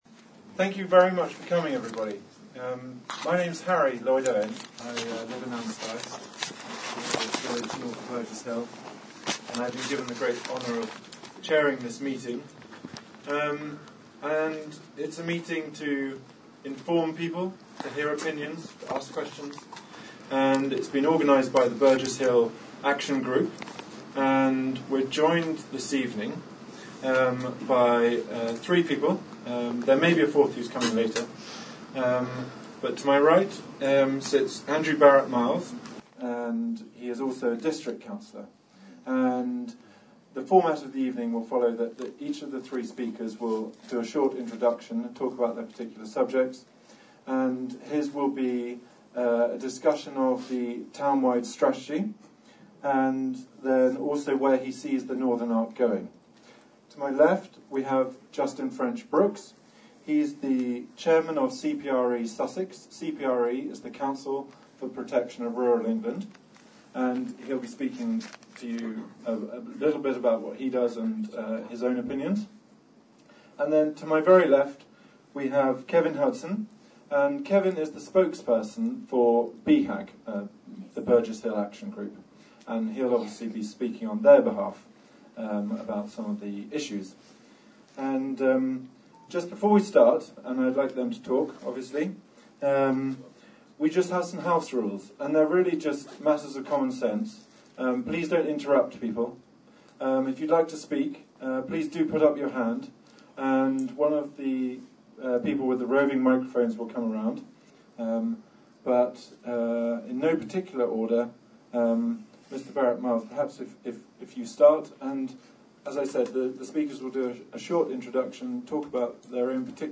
The full audio recording of the heated public meeting hosted by the Burgess Hill Action Group in order to raise awareness of the unfair allocation of 6,000+ new homes for Burgess Hill
A cracking hour-long question and answer session with the panel followed the speeches. Let's just say that things got a little heated!